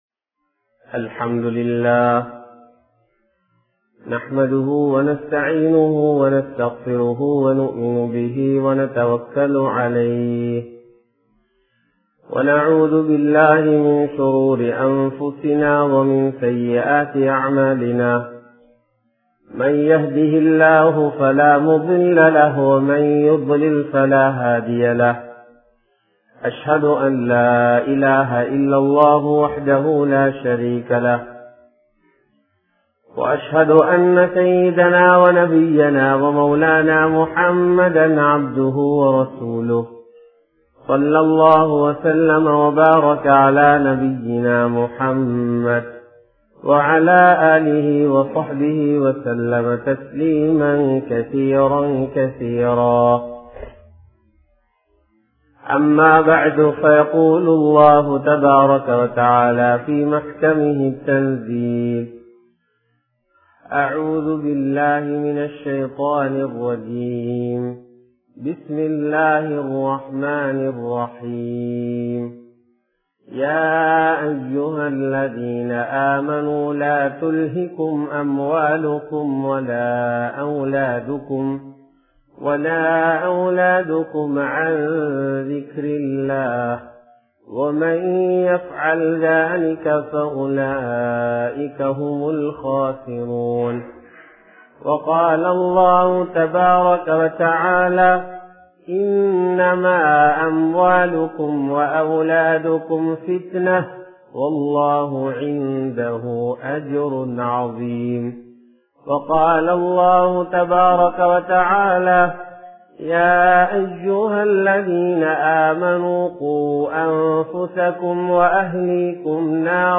Nam Sirarkalai Nabi Valiyil Valarpom(நம் சிறார்களை நபி வழியில் வளர்ப்போம்) | Audio Bayans | All Ceylon Muslim Youth Community | Addalaichenai
Meera Sahib Jumua Masjith